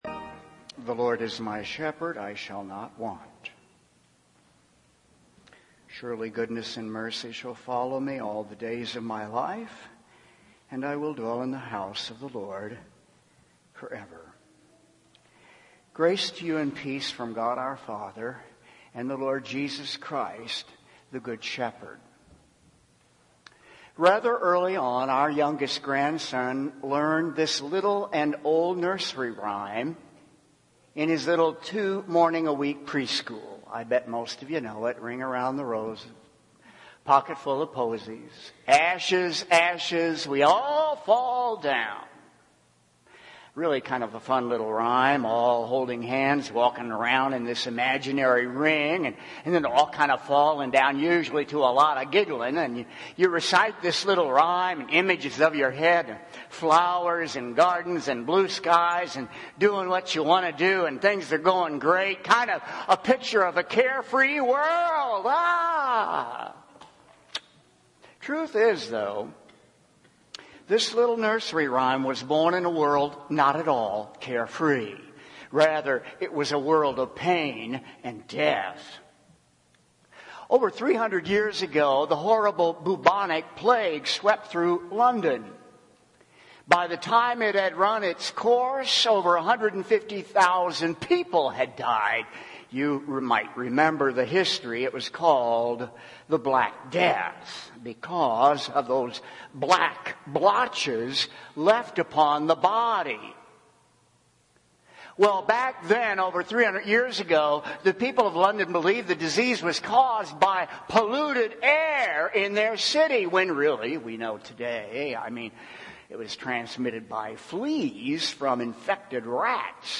Revelation 7:9-17 Audio Sermon